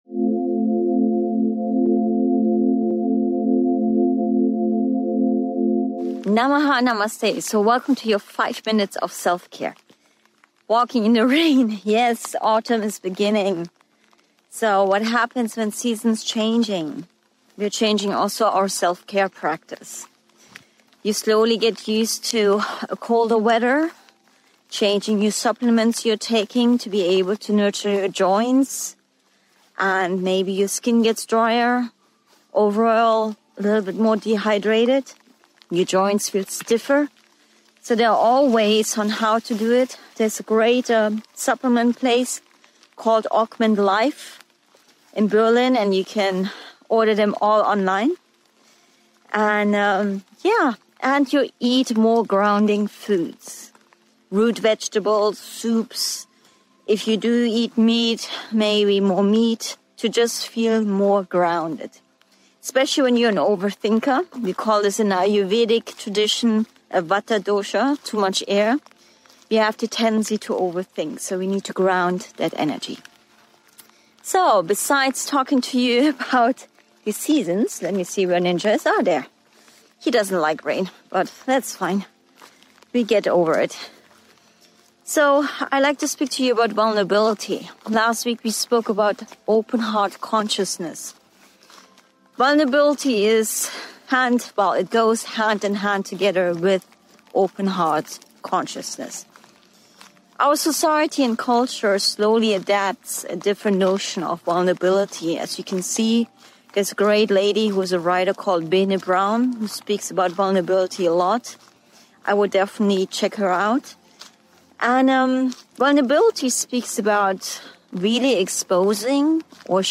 Join me on this transformative self-care journey, walking in the rain as autumn settles in. Discover how to adapt your wellness routine to the changing seasons with grounding foods, Ayurvedic practices, and supplements.